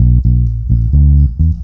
FINGERBSS4-L.wav